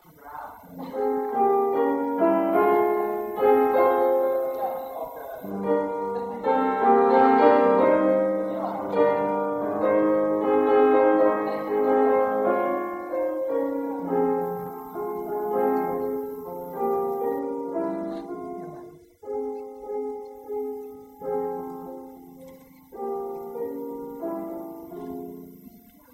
[20-,f小调中段]第一乐章谱子上只出现了一次pp[I-76]，第二乐章这个pp持续时间是比较长的，必须找到声音真正的pianissimo。
所以用弱音踏板。